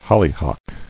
[hol·ly·hock]